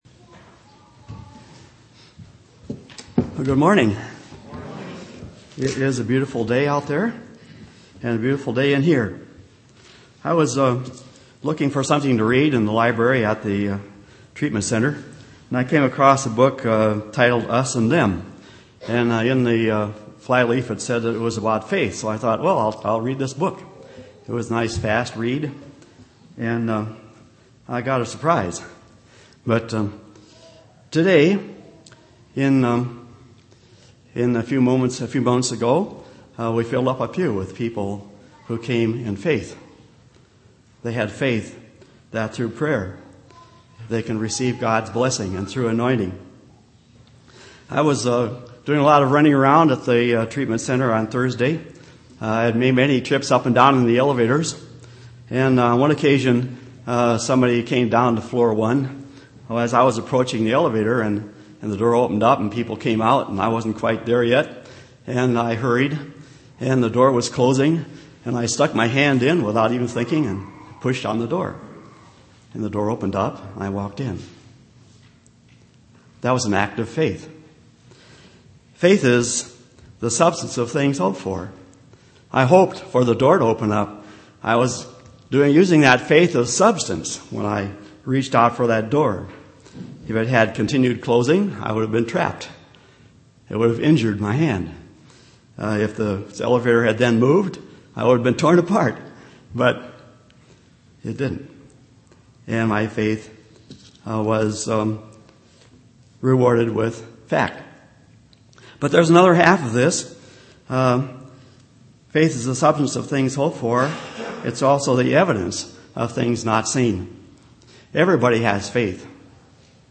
A good sermon for anyone with friends or family who belong to another church. It is a look at the examples of Christ and his disciples recognizing the faith of others. The Church of Christ can build on the faith of other Christian sects.
9/16/2007 Location: Temple Lot Local Event